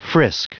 Prononciation du mot frisk en anglais (fichier audio)
Prononciation du mot : frisk